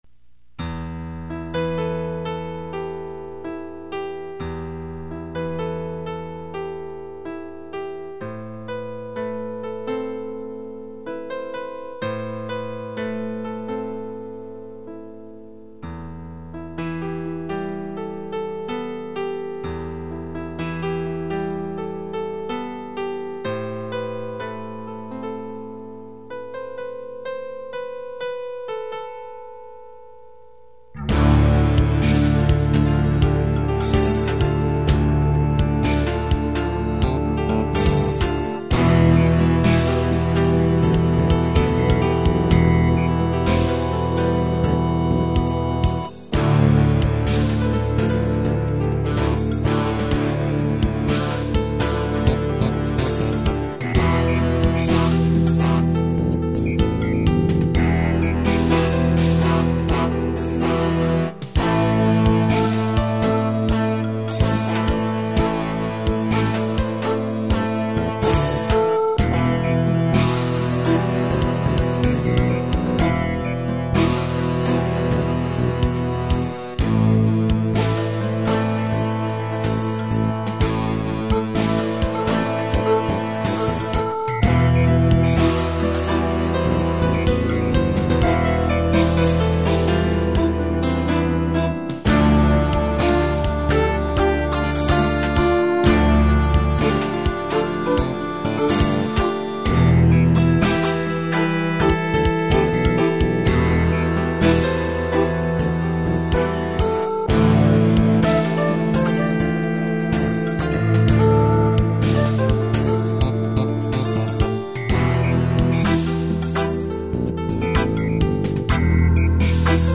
Opera Am    3.7 Mb            Divertimento en Am